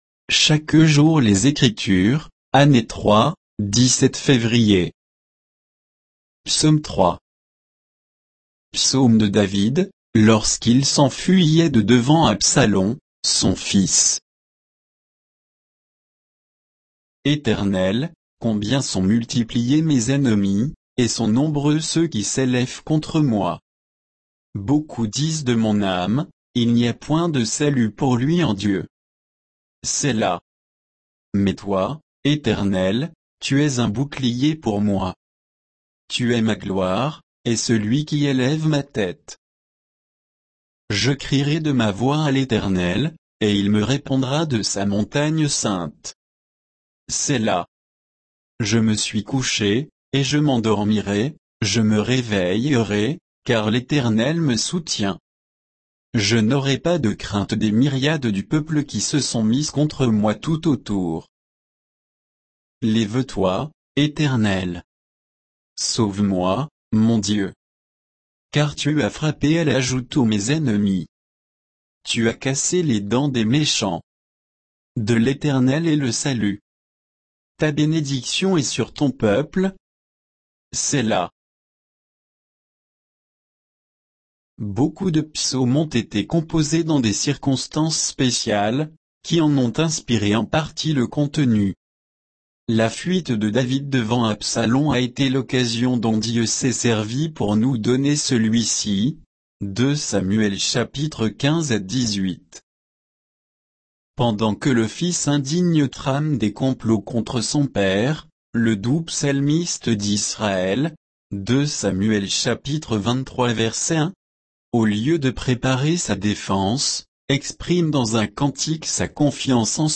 Méditation quoditienne de Chaque jour les Écritures sur Psaume 3